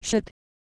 Worms speechbanks
ooff3.wav